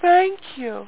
Amiga 8-bit Sampled Voice
thankyou.mp3